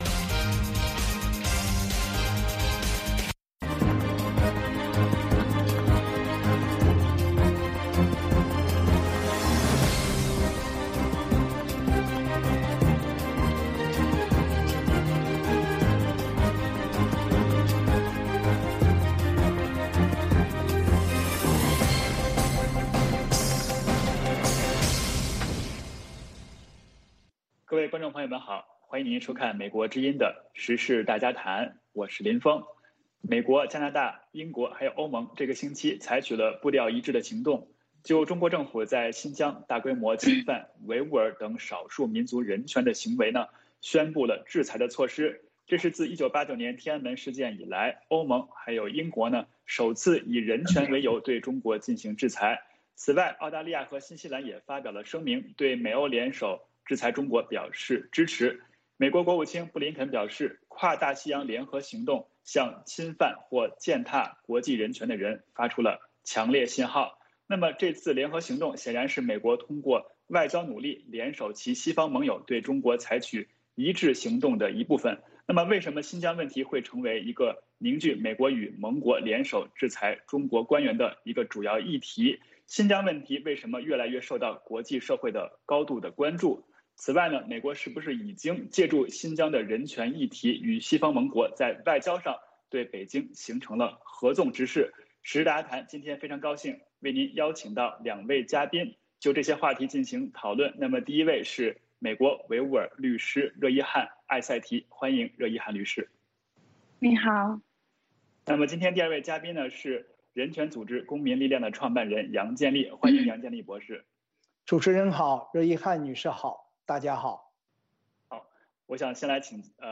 《时事大家谈》围绕重大事件、热点问题、区域冲突以及中国内政外交的重要方面，邀请专家和听众、观众进行现场对话和讨论，利用这个平台自由交换看法，探索事实。